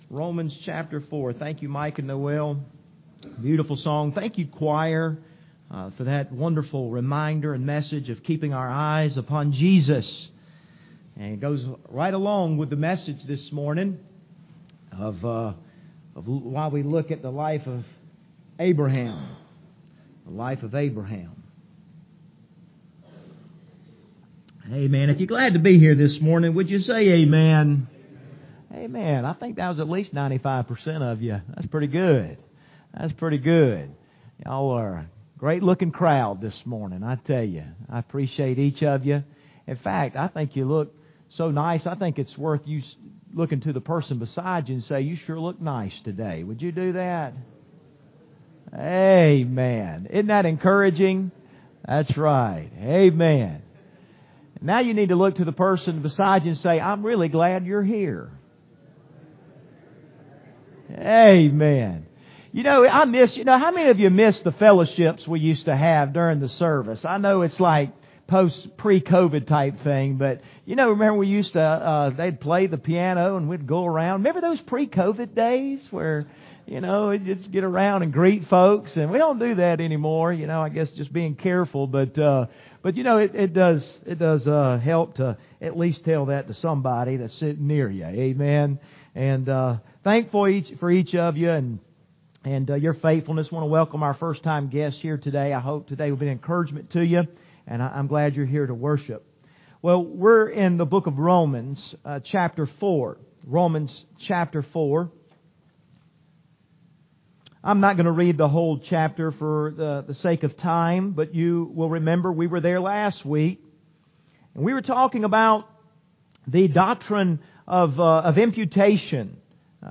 The Just Shall Live By Faith Passage: Romans 4:17-25 Service Type: Sunday Morning Topics